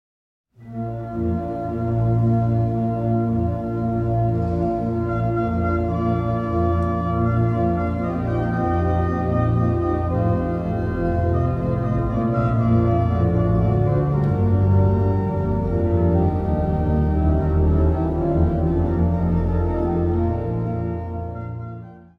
Instrumentaal | Orgel